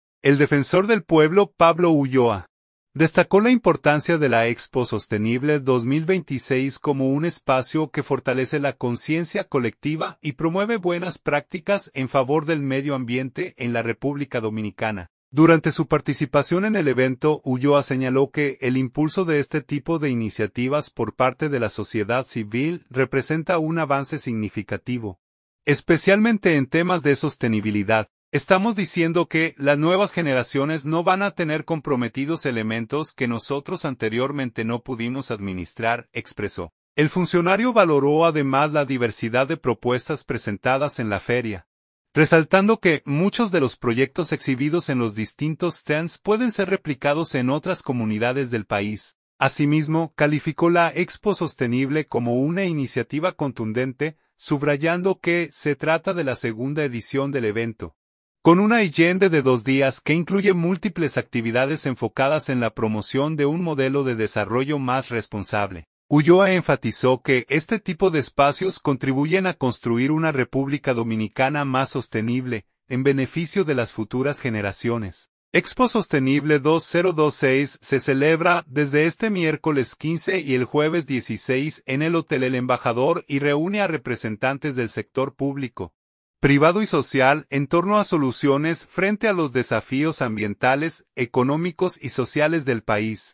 Durante su participación en el evento, Ulloa señaló que el impulso de este tipo de iniciativas por parte de la sociedad civil representa un avance significativo, especialmente en temas de sostenibilidad.